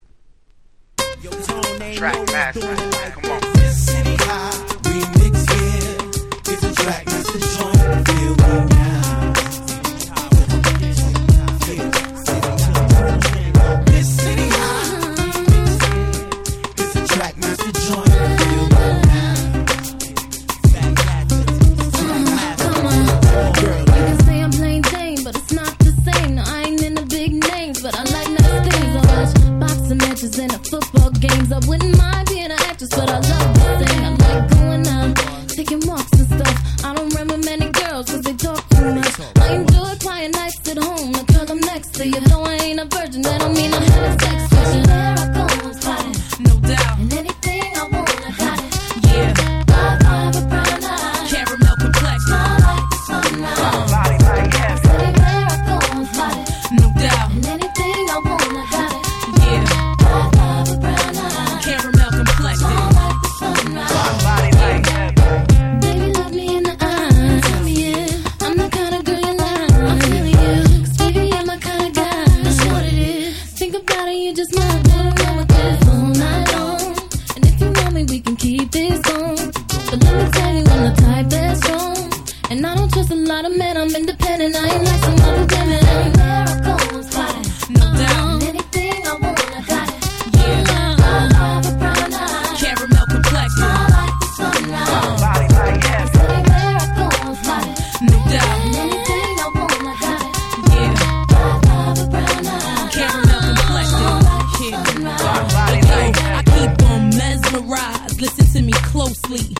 01' Super Hit R&B !!
シティハイ イヴ 00's キャッチー系